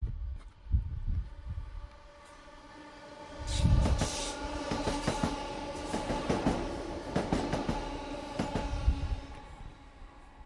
来自伦敦哈克尼E8区的现场录音 " 7路客运列车
描述：旅客列车
Tag: 哈克尼 铁路 火车 铁路 车站